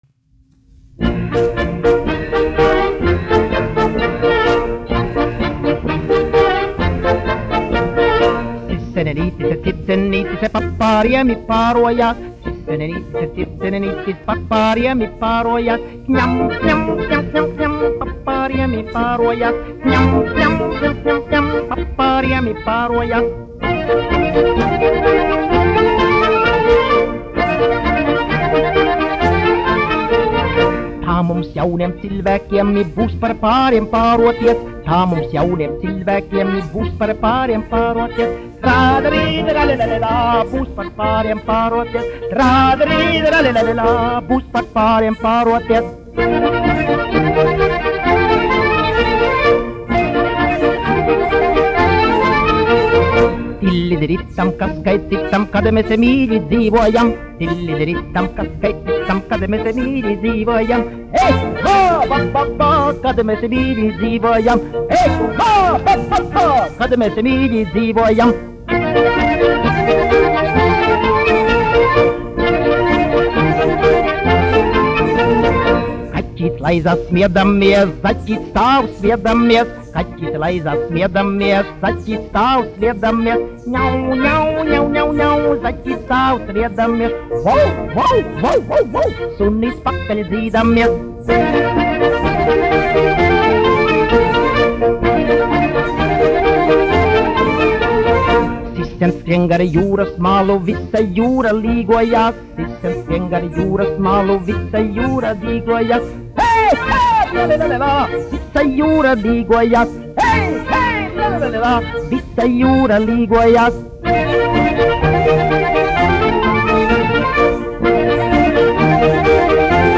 1 skpl. : analogs, 78 apgr/min, mono ; 25 cm
Polkas
Populārā mūzika
Latvijas vēsturiskie šellaka skaņuplašu ieraksti (Kolekcija)